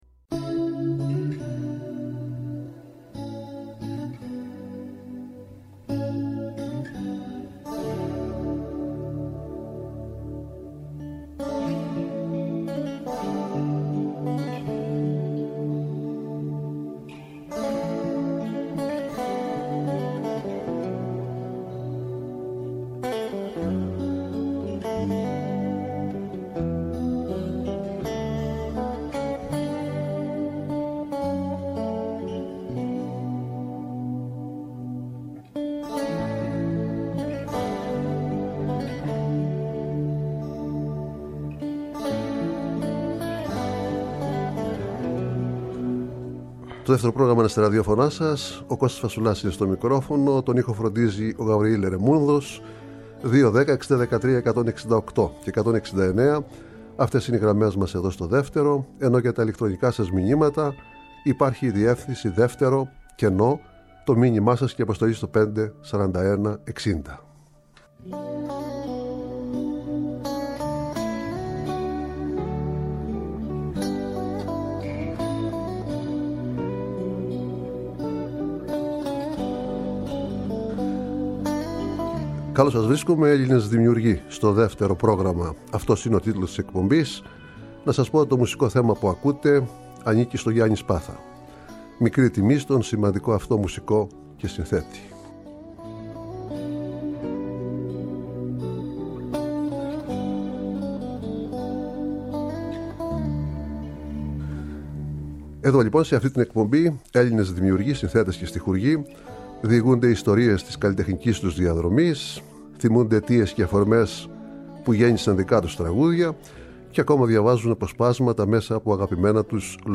Live στο Studio
Συνεντεύξεις